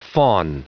Prononciation du mot fawn en anglais (fichier audio)
Prononciation du mot : fawn